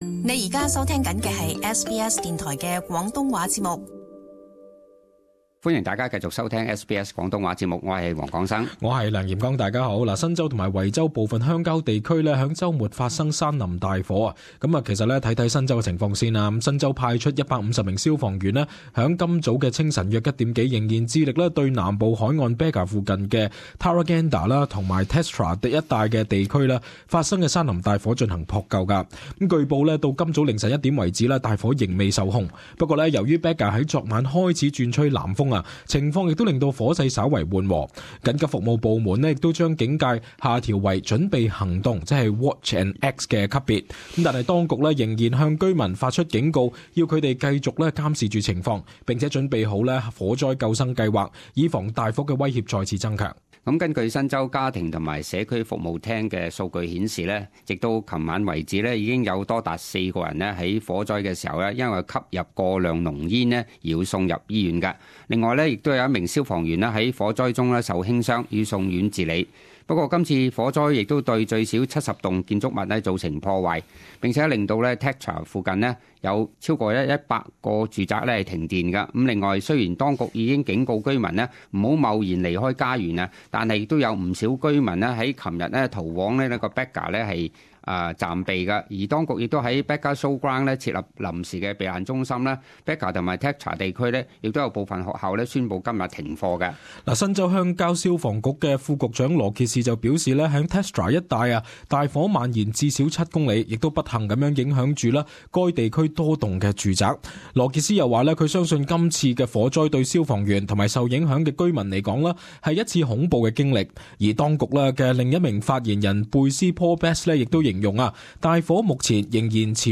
【時事報導】新州及維州鄉郊山林大火持續